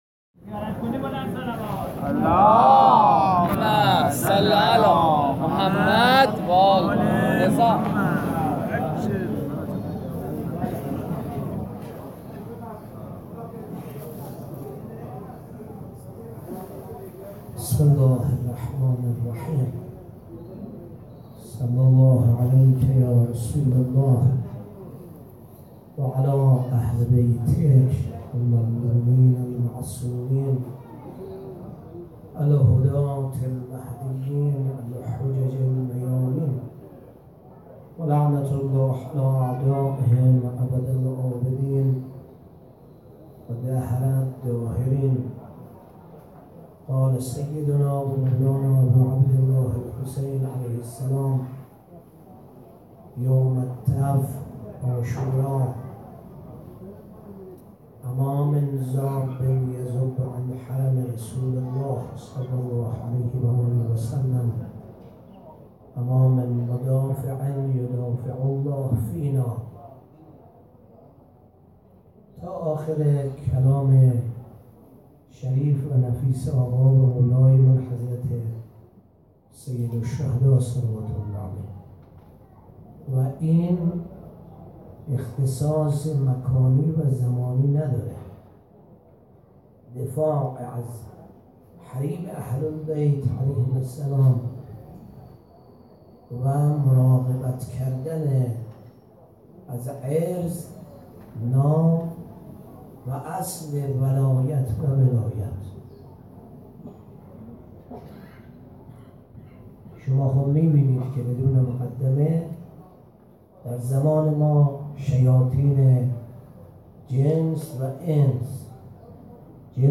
19 اردیبهشت 97 - حسینیه کرمانیها - سخنرانی